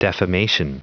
Prononciation du mot defamation en anglais (fichier audio)
defamation.wav